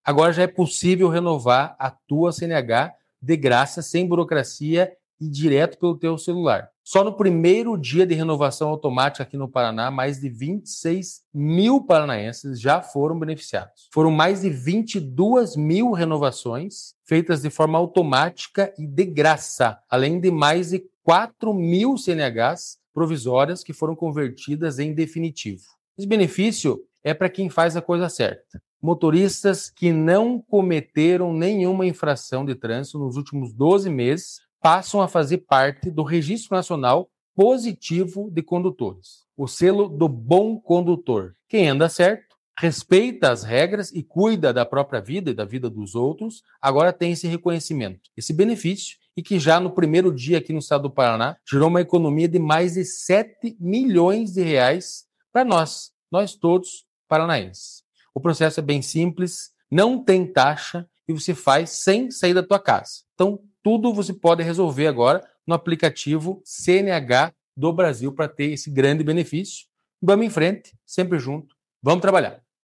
Sonora do presidente do Detran-PR, Santin Roveda, sobre o selo Bom Condutor